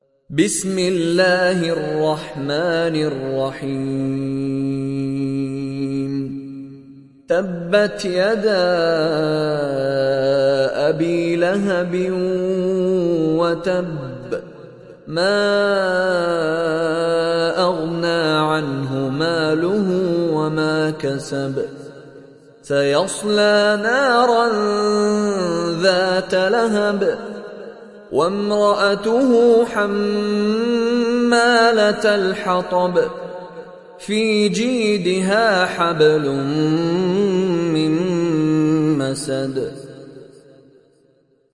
Surah Al Masad Download mp3 Mishary Rashid Alafasy Riwayat Hafs from Asim, Download Quran and listen mp3 full direct links